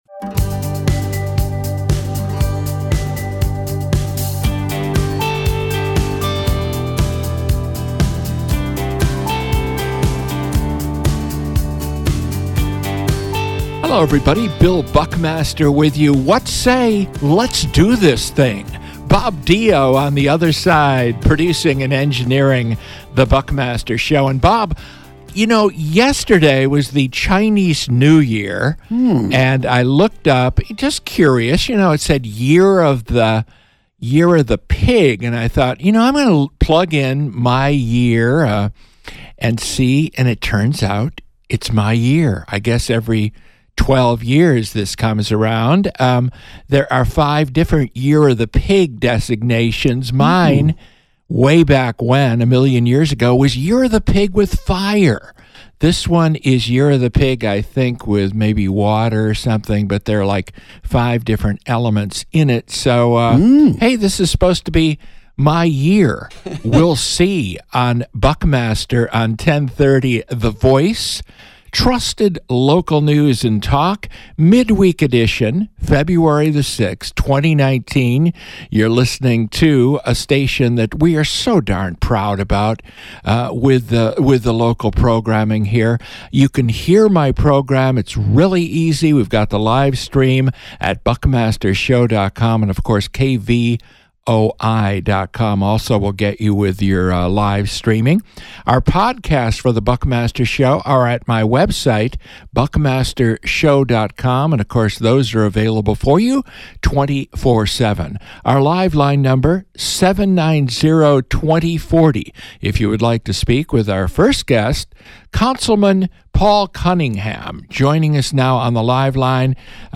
A newsmaker interview with Tucson City Councilman Paul Cunningham (D-Ward 2).